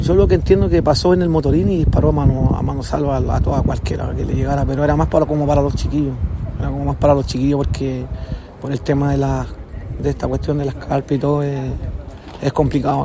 Uno de ellos, bajo reserva de identidad, relató lo sucedido.
cu-testigo-meiggs.mp3